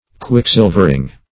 Search Result for " quicksilvering" : The Collaborative International Dictionary of English v.0.48: Quicksilvering \Quick"sil`ver*ing\, n. The mercury and foil on the back of a looking-glass.